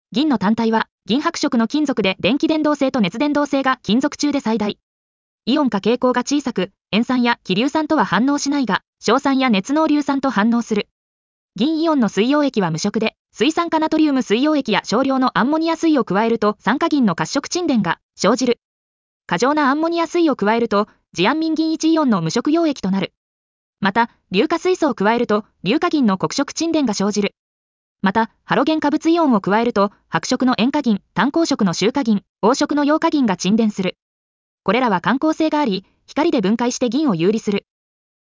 • 耳たこ音読では音声ファイルを再生して要点を音読します。通学時間などのスキマ学習に最適です。
耳たこ音読｜銀の性質
ナレーション 音読さん